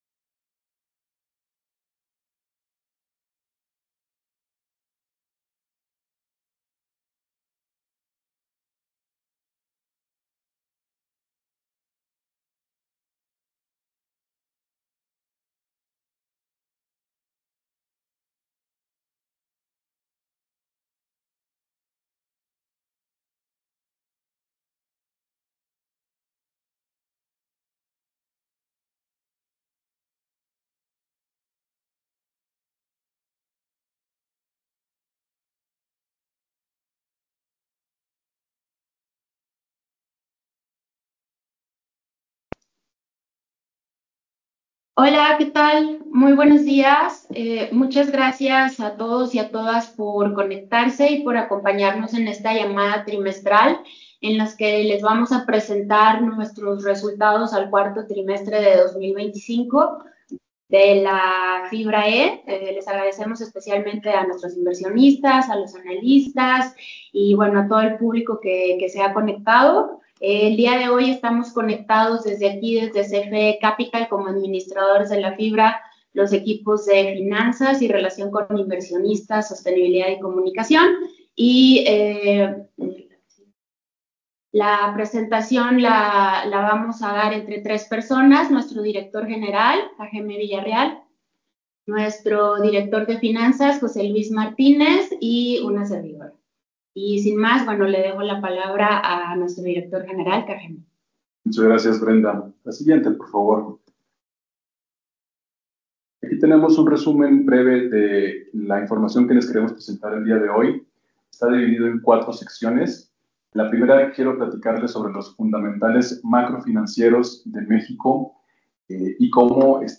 4T25 Llamada con inversionistas - CFE Capital
03_4t25_audio_llamada_con_inversionistas.mp3